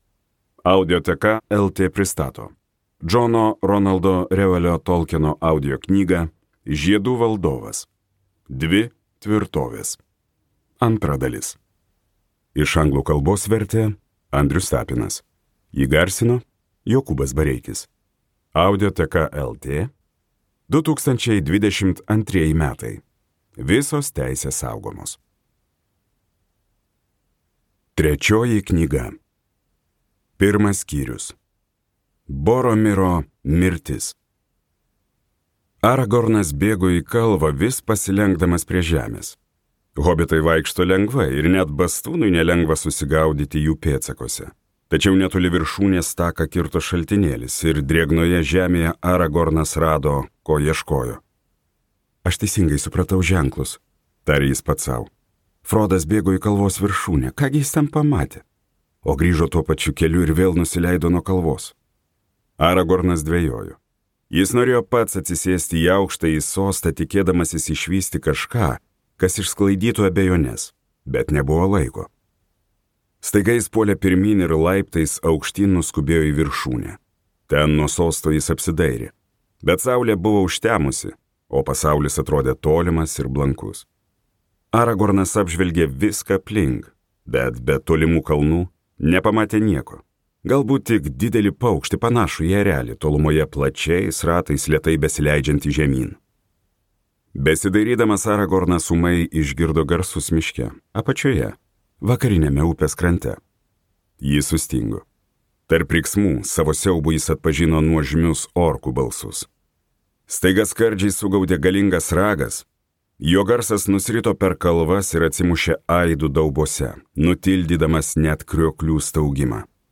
Balsas: Jokūbas Bareikis